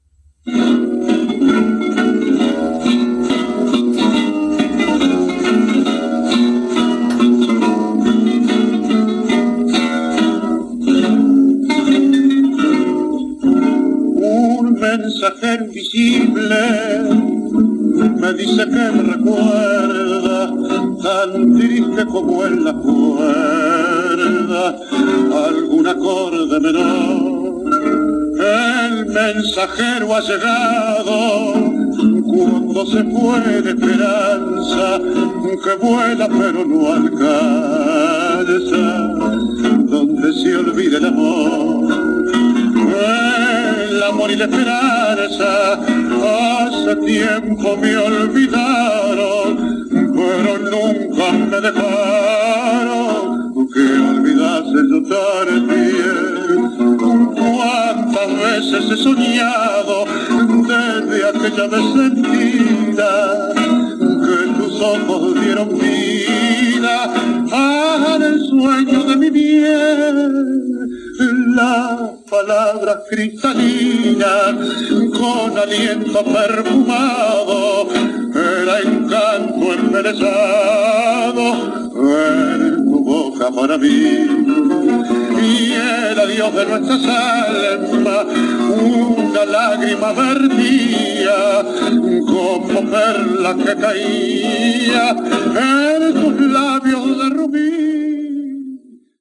Tango
Musical
guitarristes